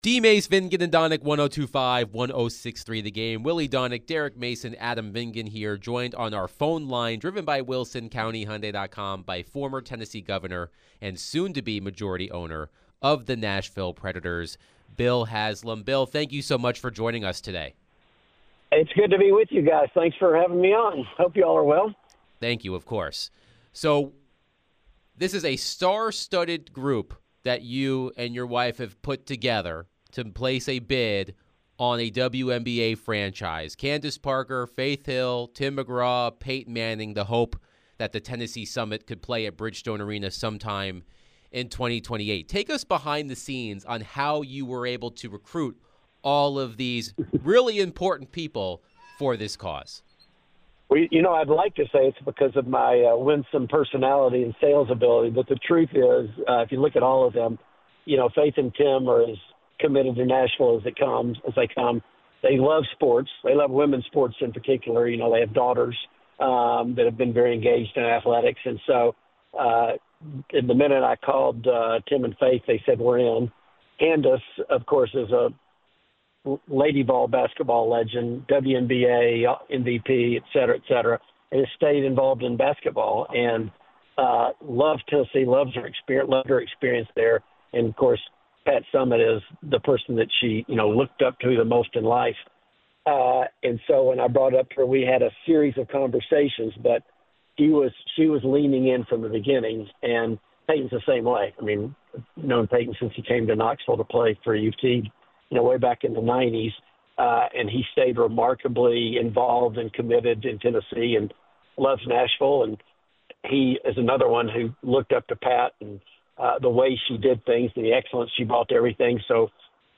Former Tennessee Governor/Soon to be Nashville Predators owner Bill Haslam joined them to discuss the bid for the WNBA to Nashville